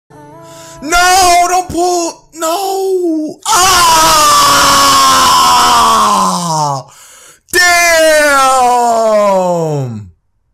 Play Flightreacts Crying - SoundBoardGuy
flightreacts-crying.mp3